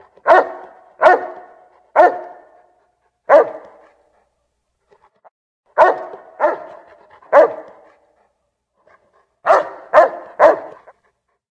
Category:Old World Blues endgame narrations Du kannst diese Datei nicht überschreiben.